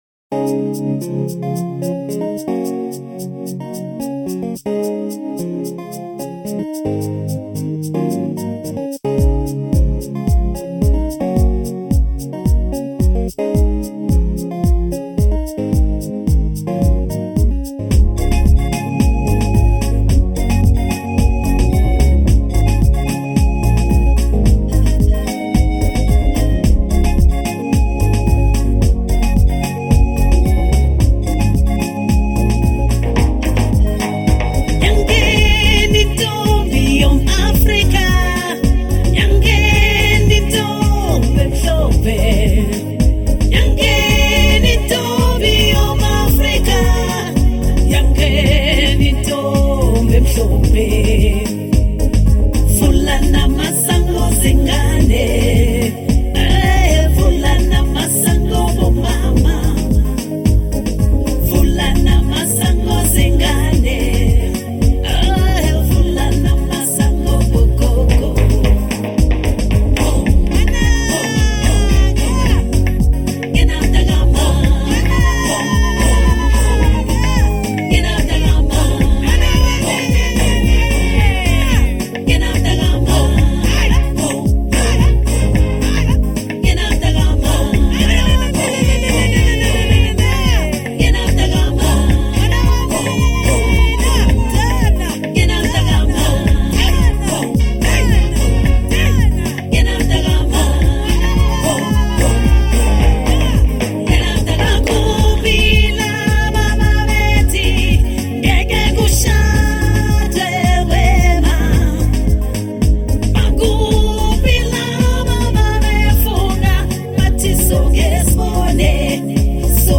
March 19, 2025 Publisher 01 Gospel 0